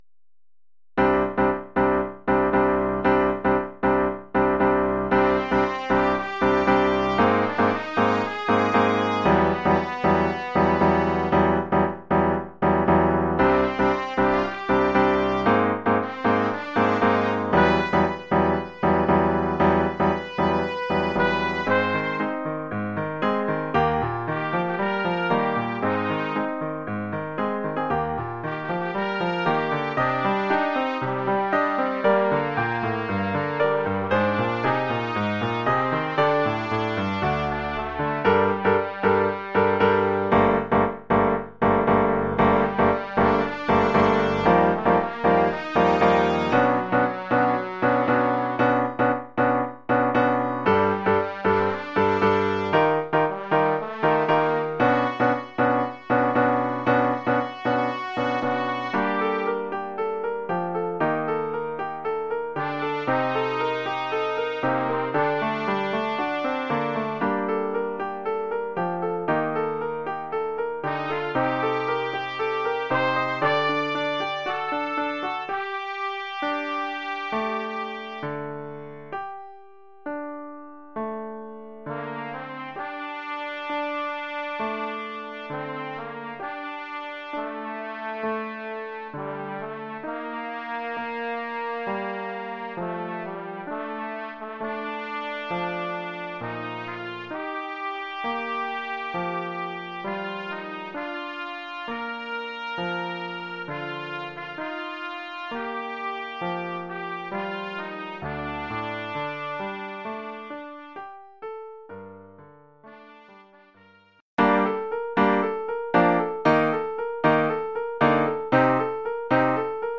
Formule instrumentale : Trompette et piano
Oeuvre pour trompette ou cornet
ou bugle et piano..